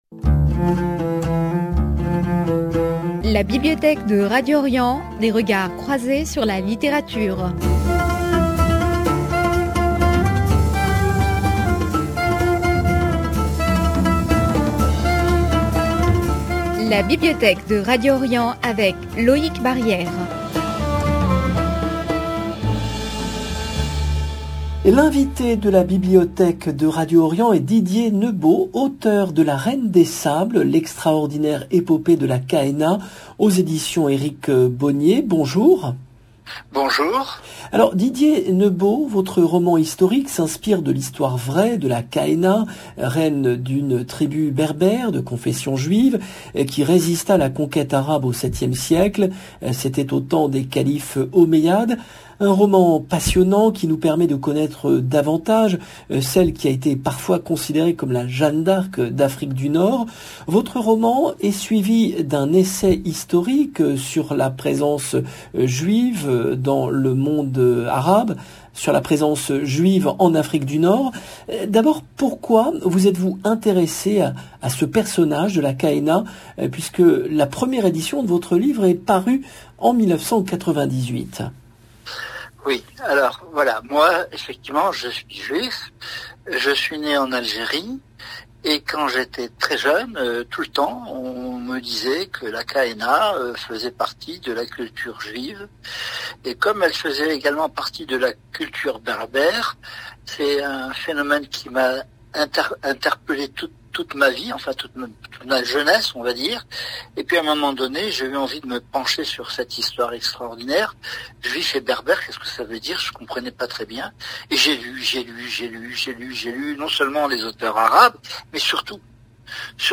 invité de la Bibliothèque de Radio Orient